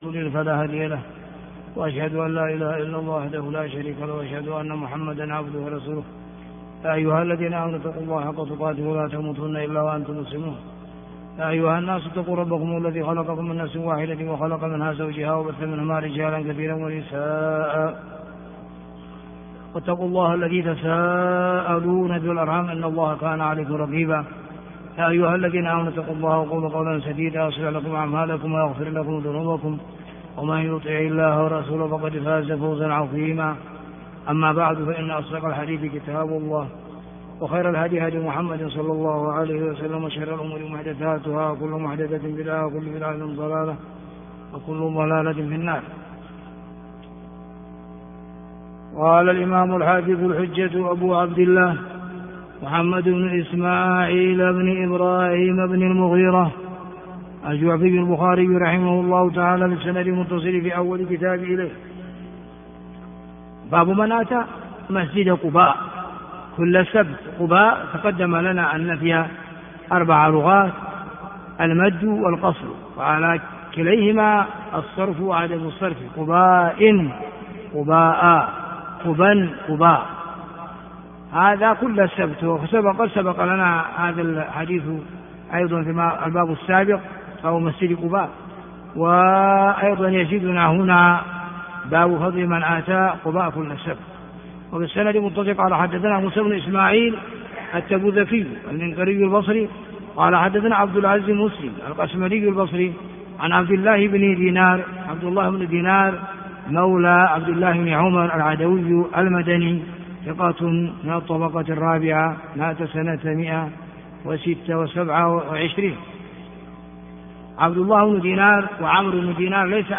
الدرس 091 - كتاب فضل الصلاة في مسجدي مكة والمدينة - بَابُ مَنْ أَتَى مَسْجِدَ قُبَاءٍ كُلَّ سَبْتٍ - ح 1193